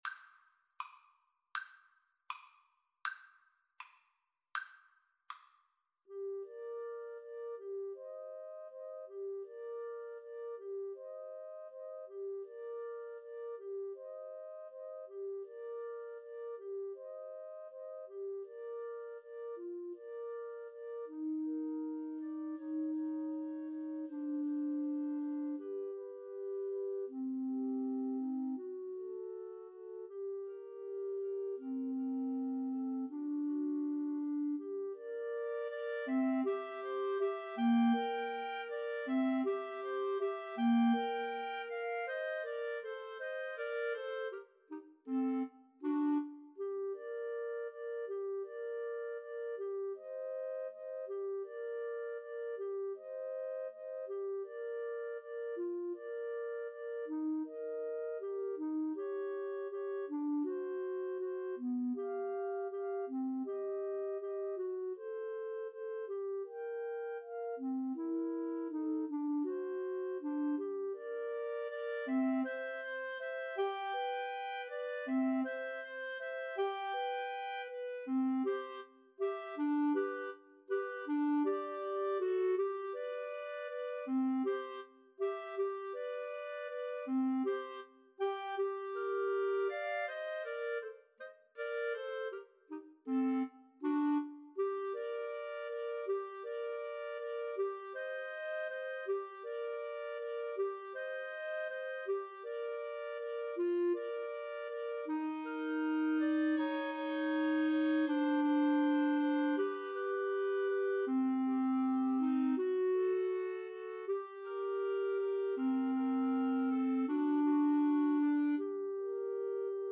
~ = 100 Andante
Classical (View more Classical Clarinet Trio Music)